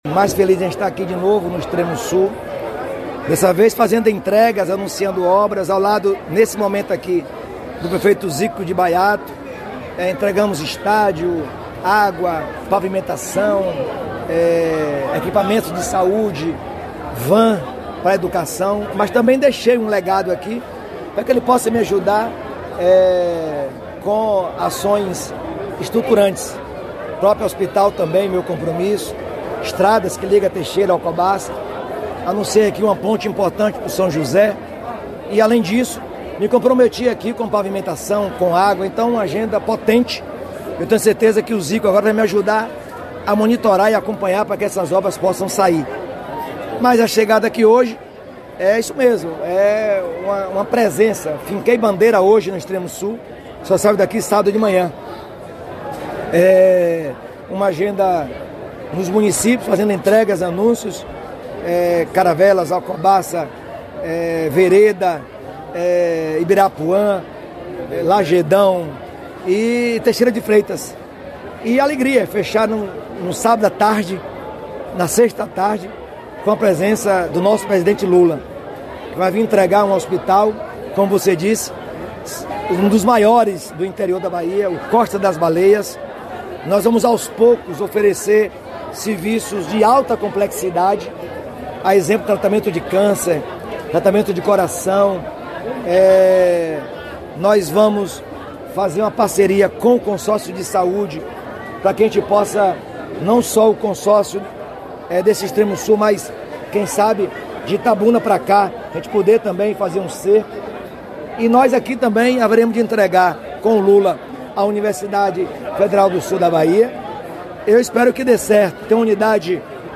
🎙Jerônimo Rodrigues – Governador da Bahia